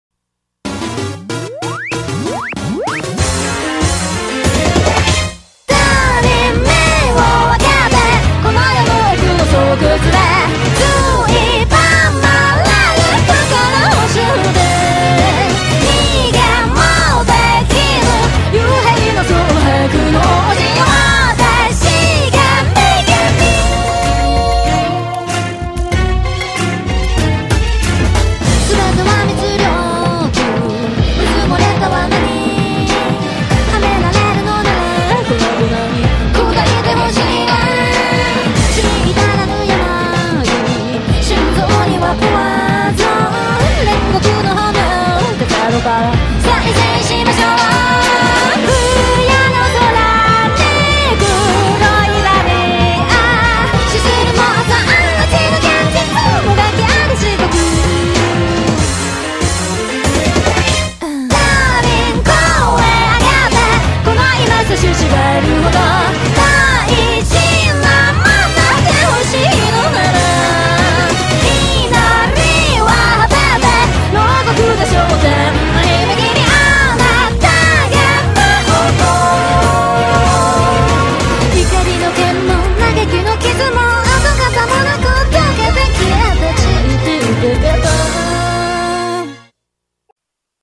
BPM: 189,9